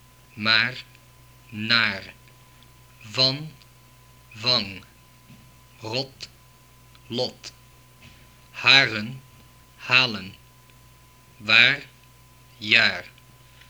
Below is a recording of minimal pair words in Dutch that prove that these sounds exist in Dutch as phonemes, not allophones.
While there are more consonants than this in Dutch, these were the ones present in the word list recordings from the UCLA phonetics lab archive.